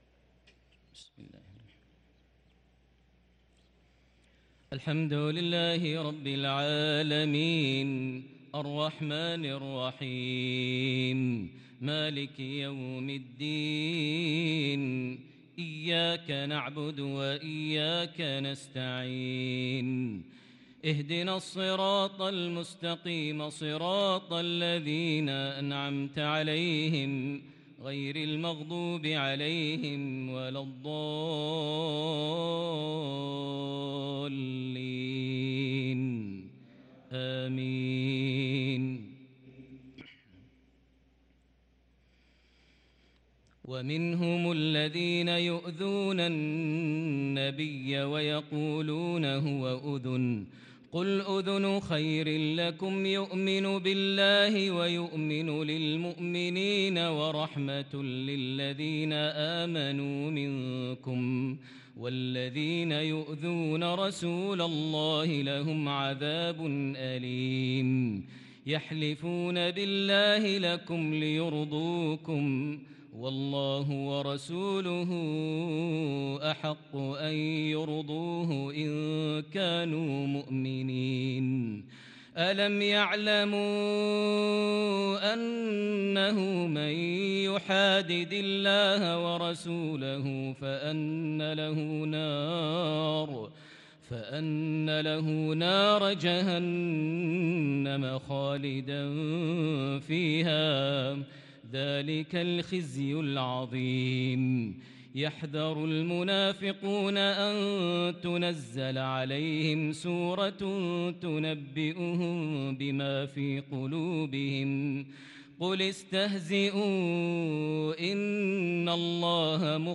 صلاة العشاء للقارئ ماهر المعيقلي 13 ربيع الآخر 1444 هـ
تِلَاوَات الْحَرَمَيْن .